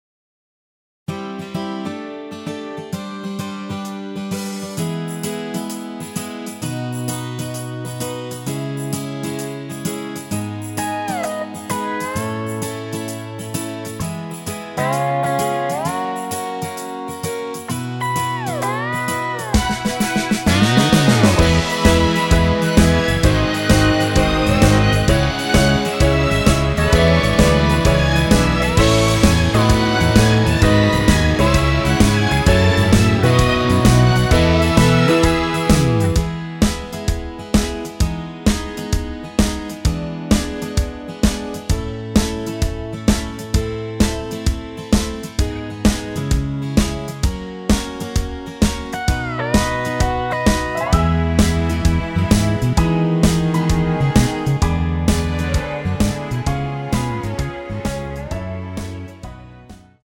전주 없는 곡이라 전주 2마디 만들어 놓았습니다.
엔딩이 페이드 아웃이라 라이브 하시기 편하게 엔딩을 만들어 놓았습니다.
◈ 곡명 옆 (-1)은 반음 내림, (+1)은 반음 올림 입니다.
앞부분30초, 뒷부분30초씩 편집해서 올려 드리고 있습니다.